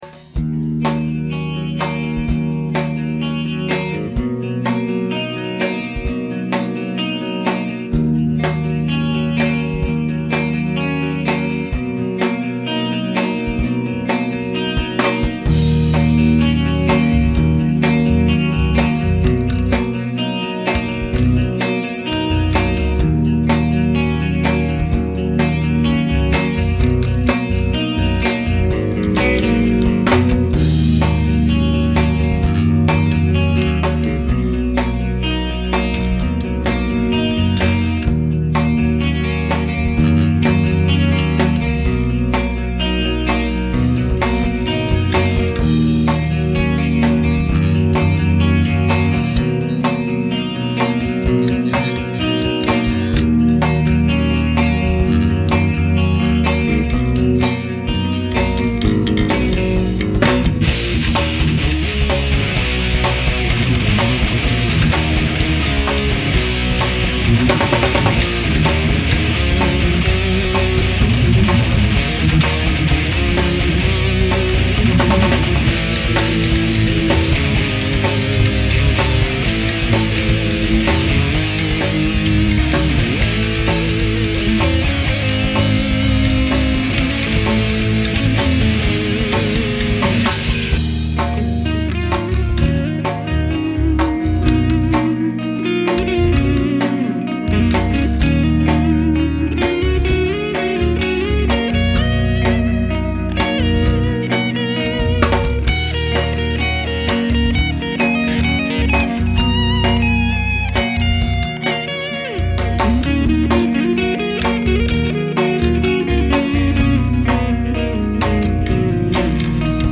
Repet 22/01/06
Avec chant, la meilleure version pour le moment